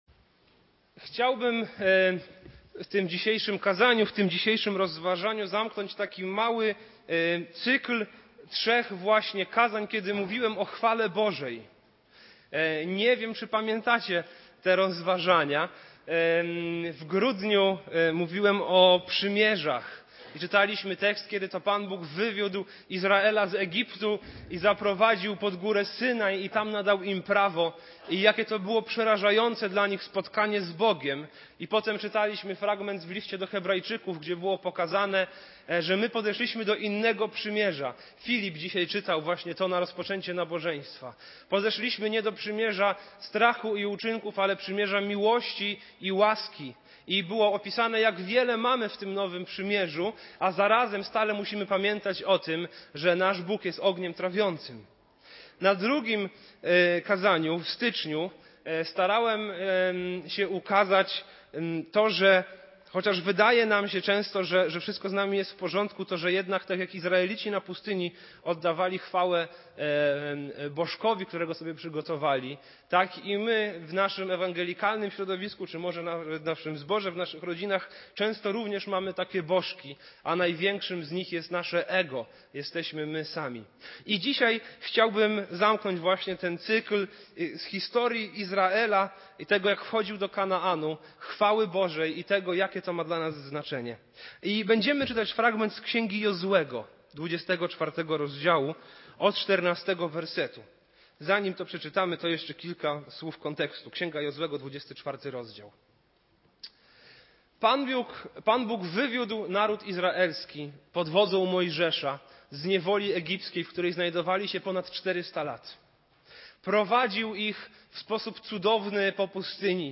- Pierwszy Zbór | Pokolenia wierne Bogu